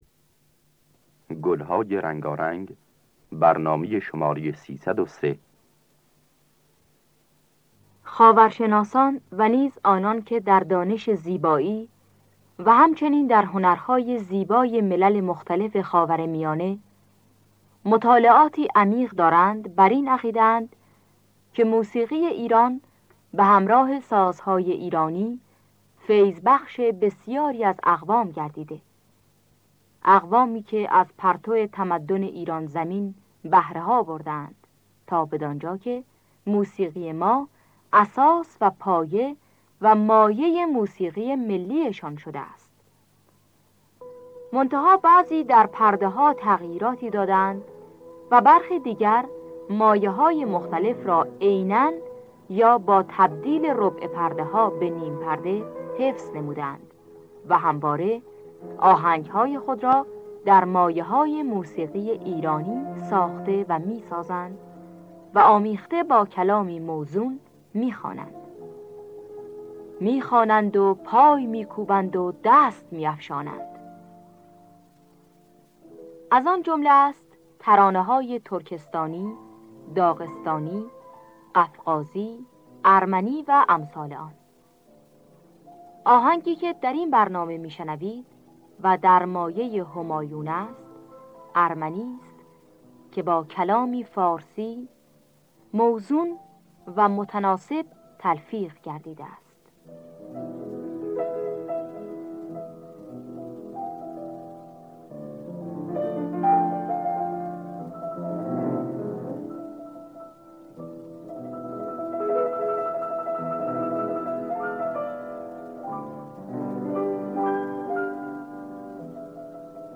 گلهای رنگارنگ ۳۰۳ - همایون
خوانندگان: الهه عبدالوهاب شهیدی نوازندگان: حبیب‌الله بدیعی انوشیروان روحانی جواد معروفی